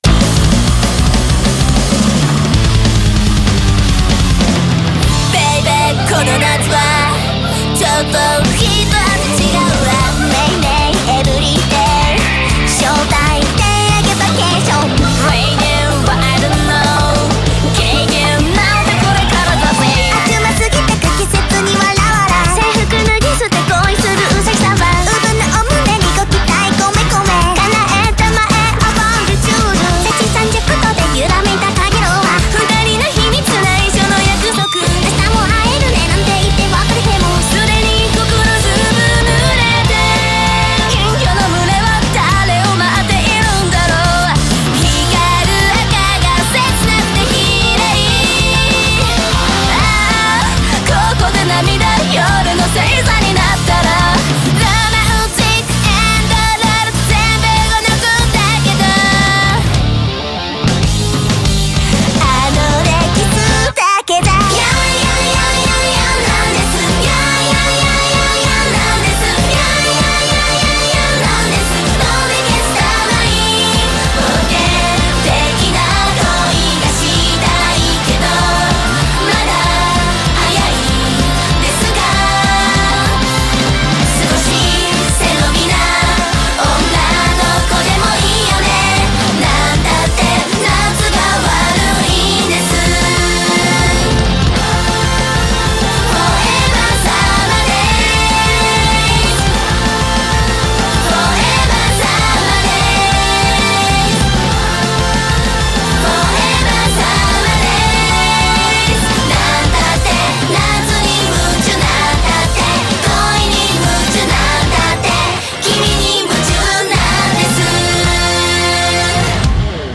BPM193
Audio QualityPerfect (Low Quality)